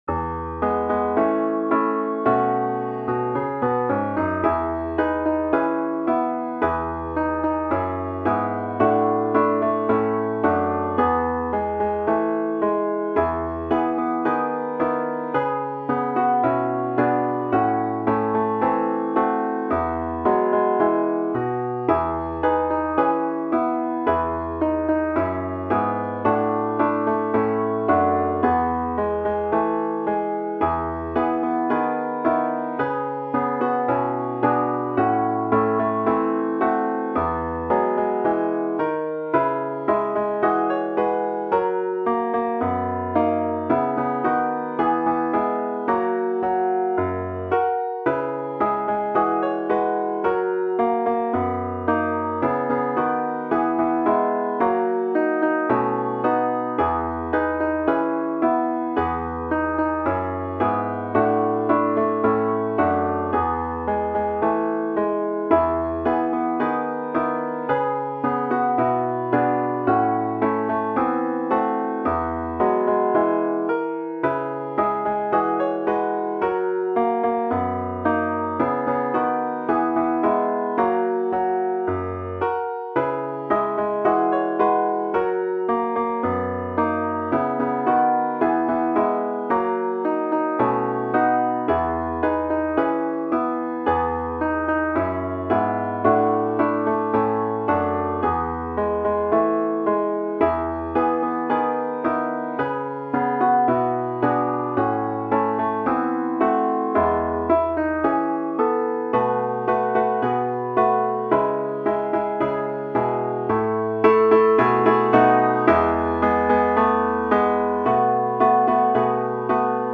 Für 4 Gitarren
Pop/Rock/Elektronik
Ensemblemusik
Quartett
Gitarre (4)